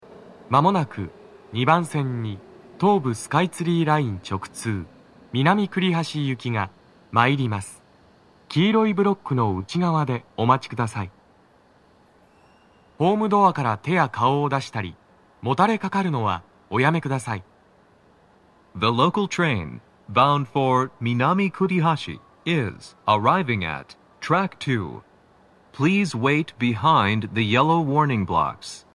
スピーカー種類 TOA天井型
鳴動は、やや遅めです。
男声